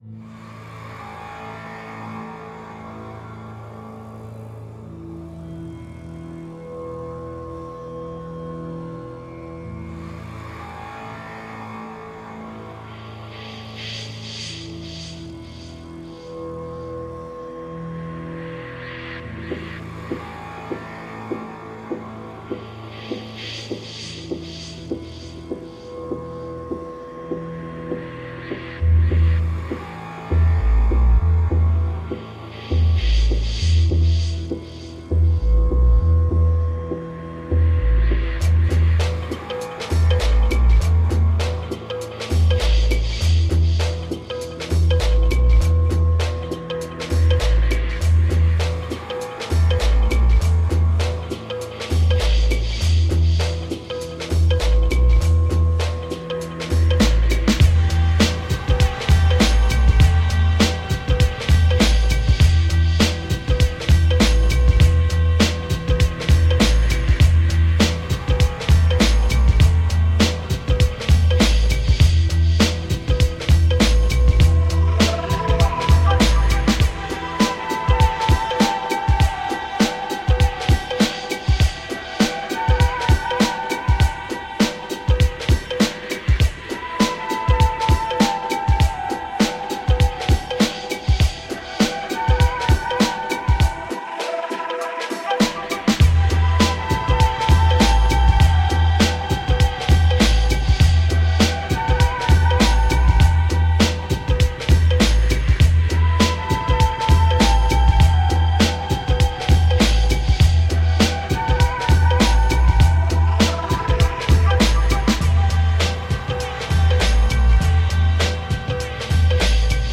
Chillout music for late nights and early mornings.
Tagged as: Electronica, Ambient, Chillout